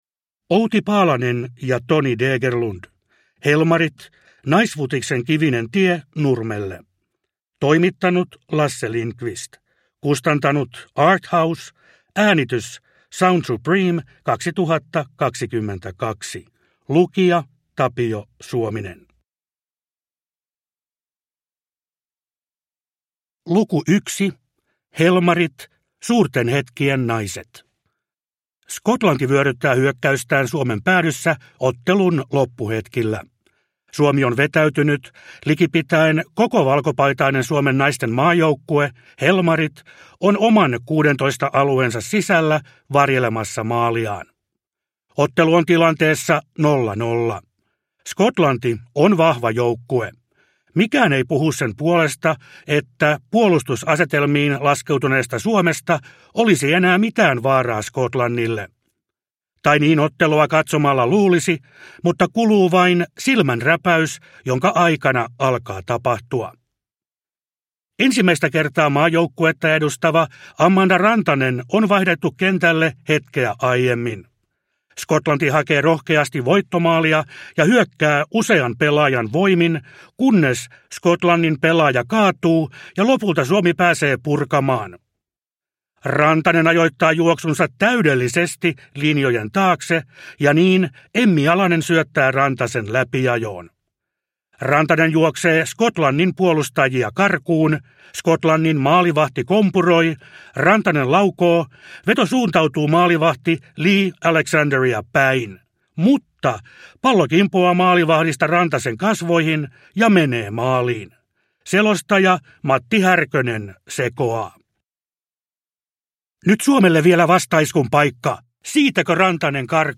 Helmarit – Ljudbok – Laddas ner
Suomen naisten jalkapallomaajoukkue Helmareiden tarina - selostajalegenda Tapio Suomisen lukemana!Naisten jalkapallon EM-kisat pelataan heinäkuussa 2022 lajin syntysijoilla Englannissa.